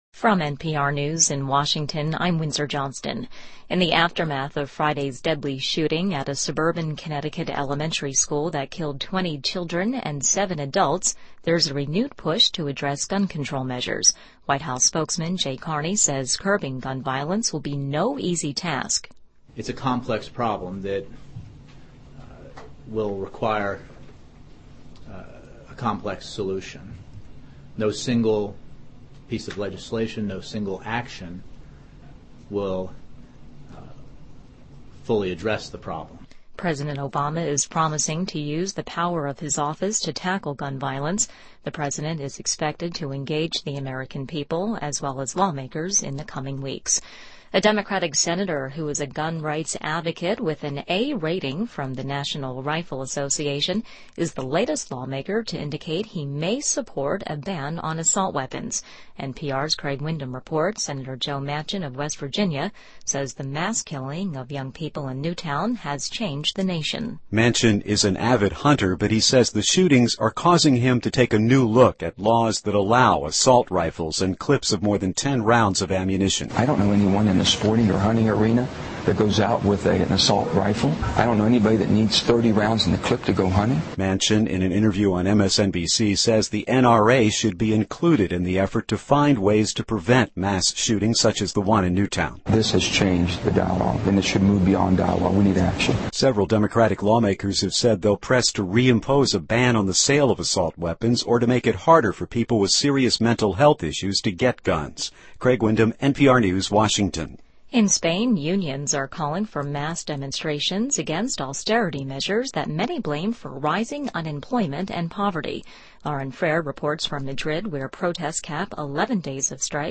NPR News,2012-12-18